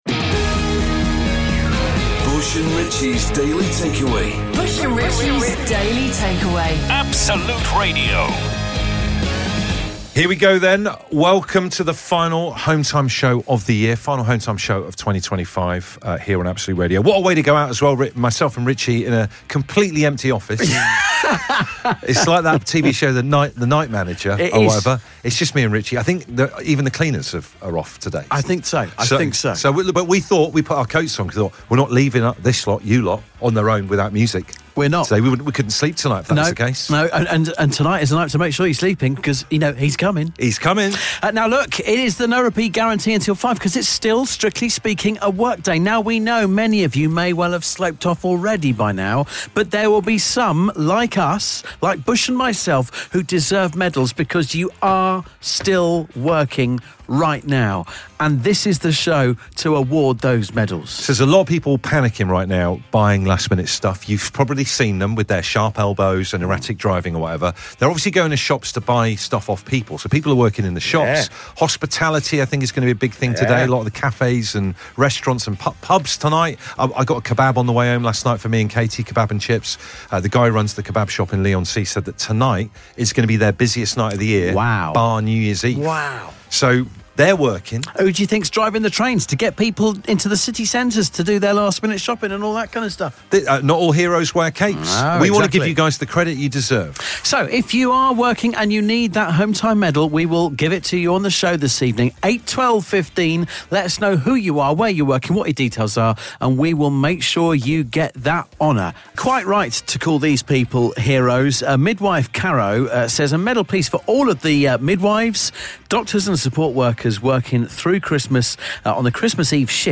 The best bits from 3 hours of nonsense presented by 2 confused Dads.